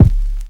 JayDee808kick.wav